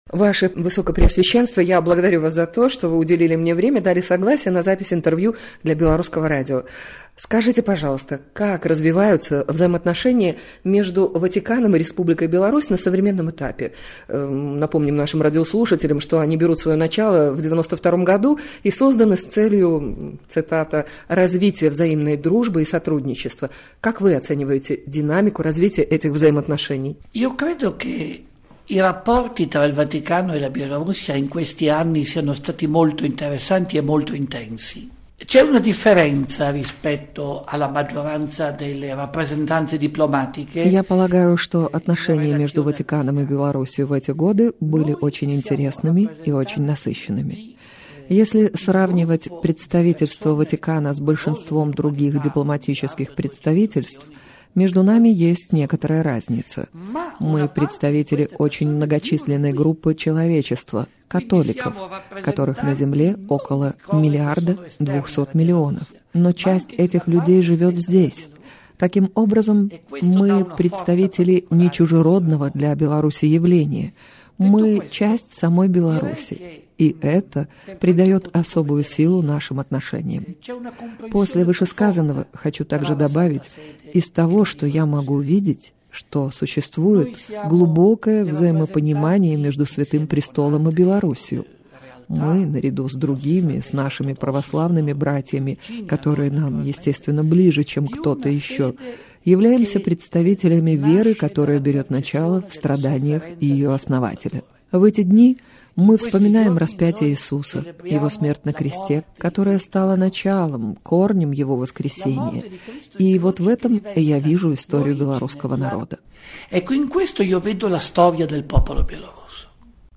Апостальскі Нунцый у Беларусі арцыбіскуп Клаўдыё Гуджэроцці даў інтэрв'ю , якое было запісана для праграм Міжнароднага радыё "Беларусь" Беларускага радыё Нацыянальнай дзяржаўнай тэлерадыёкампаніі Рэспублікі Беларусь. У інтэрв’ю арцыбіскуп распавядае пра ўзаемаадносіны паміж Ватыканам і Рэспублікай Беларусь на сучасным этапе, пра тое, у чым заключаецца мэта дыпламатыі Ватыкана.